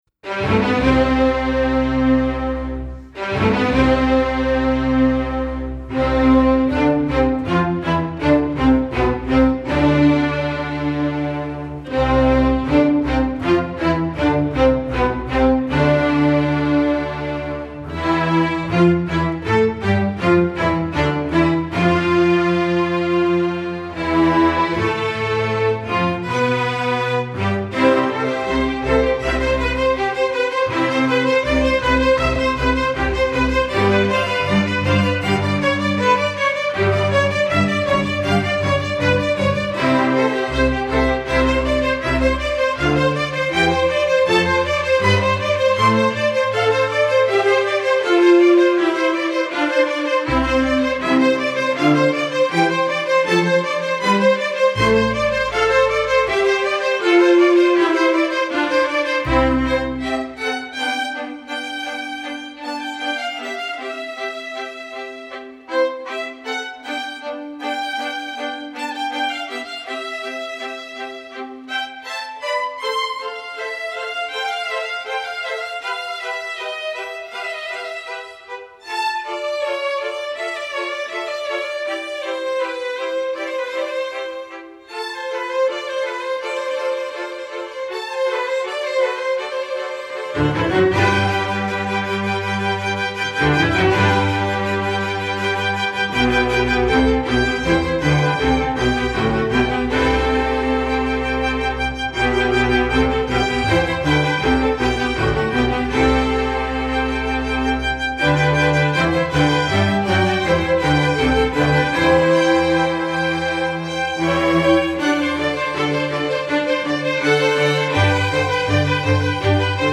masterwork arrangement, opera